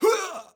death_man.wav